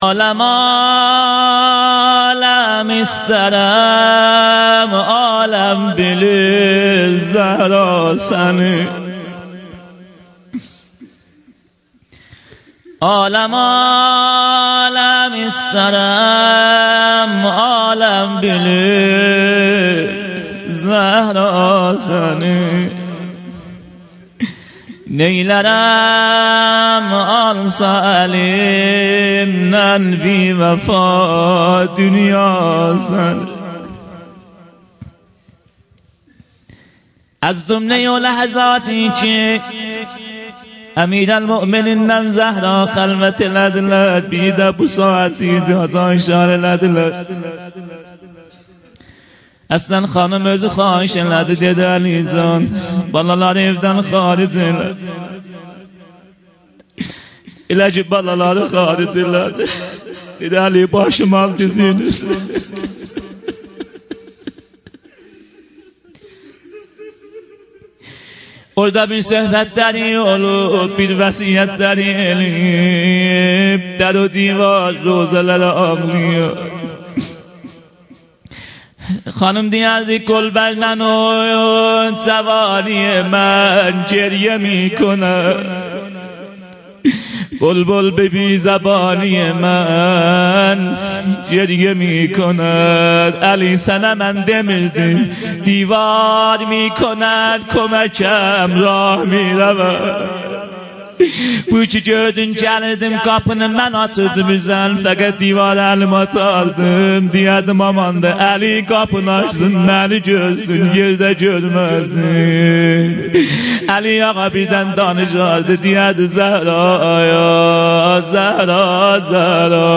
هیأت محبان اهل بیت علیهم السلام چایپاره
فاطمیه 96- عصر روز شهادت